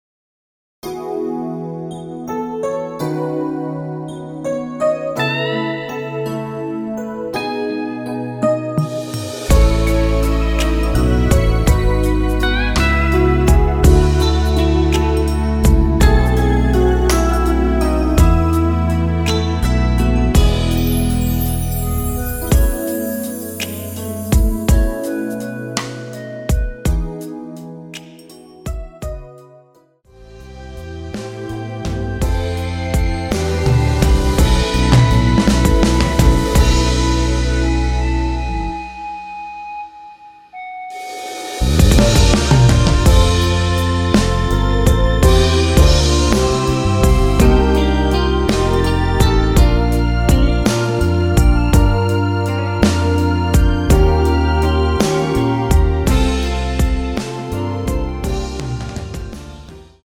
원키에서(+2)올린 멜로디 포함된 MR입니다.
◈ 곡명 옆 (-1)은 반음 내림, (+1)은 반음 올림 입니다.
멜로디 MR이란
앞부분30초, 뒷부분30초씩 편집해서 올려 드리고 있습니다.
중간에 음이 끈어지고 다시 나오는 이유는